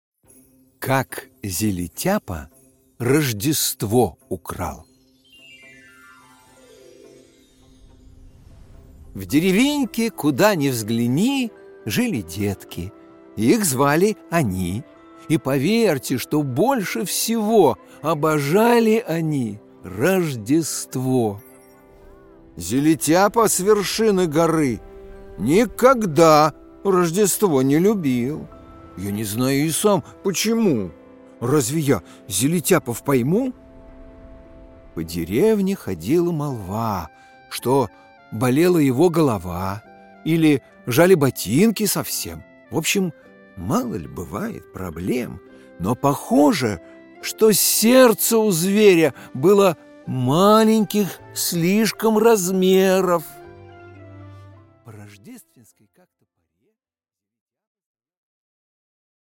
Аудиокнига Как Зелетяпа Рождество украл!